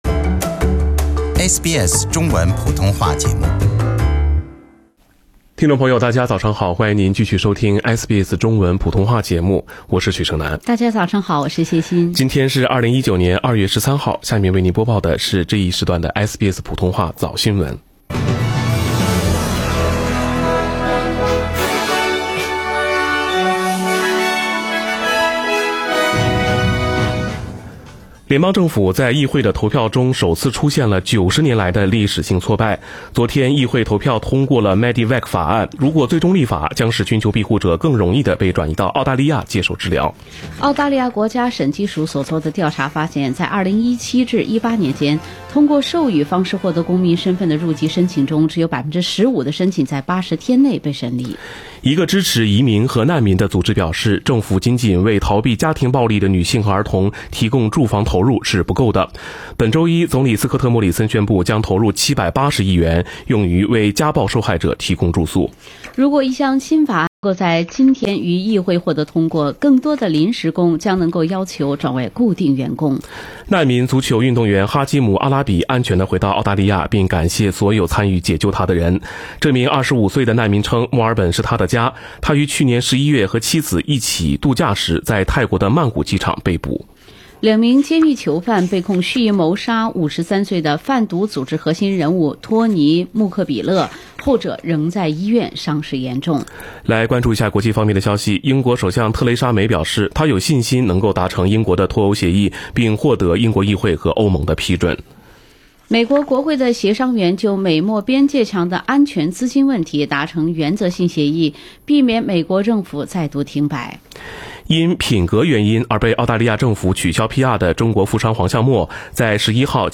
SBS 早新闻 （2月13日）
SBS Chinese Morning News Source: Shutterstock